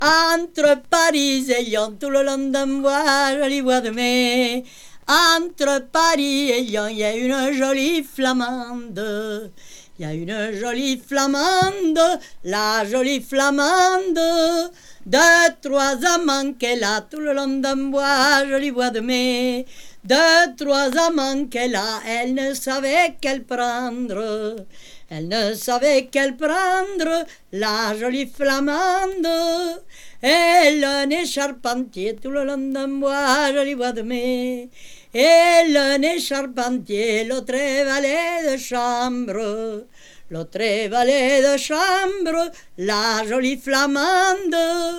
本盤ではフランスを題材に、南仏の伝承歌を収録。
Folk, World　France　12inchレコード　33rpm　Stereo